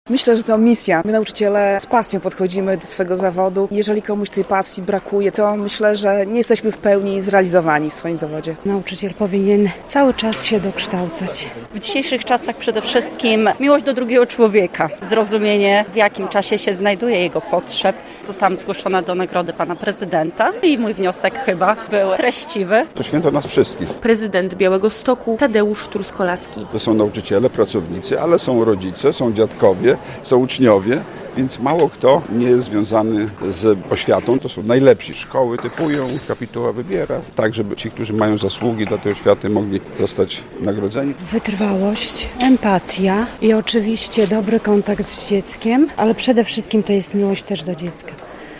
Wyróżnienia "Nauczyciel Mądry Sercem" od prezydenta Białegostoku - relacja